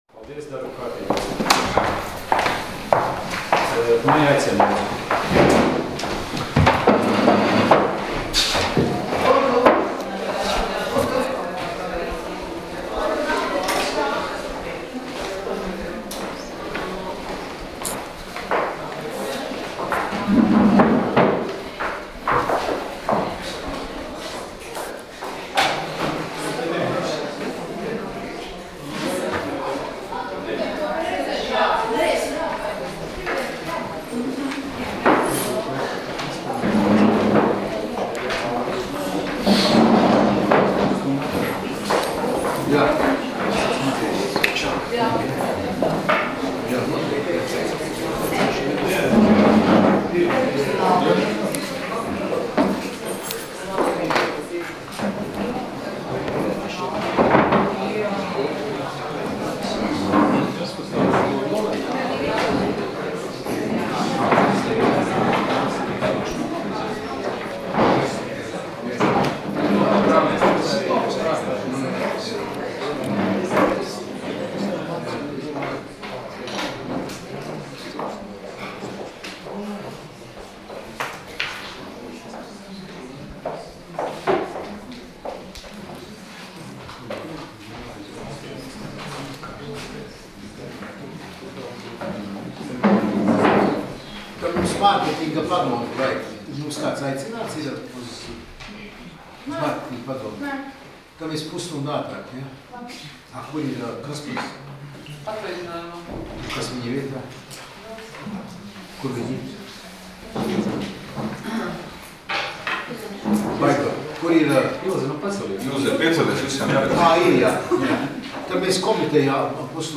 Domes sēdes 11.09.2015. audioieraksts